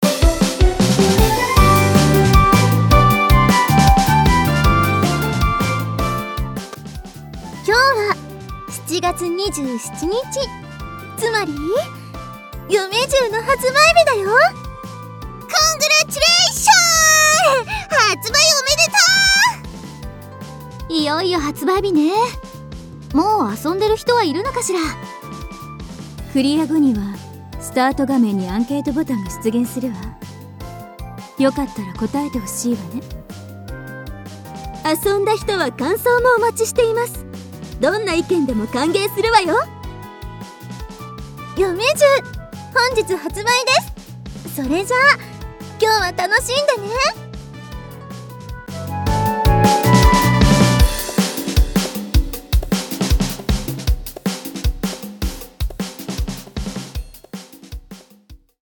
カウントダウンボイス発売当日！